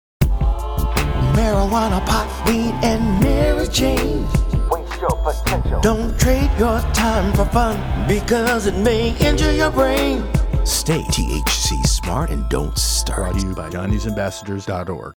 Television and Radio PSA Ads Free For You to Use!
RADIO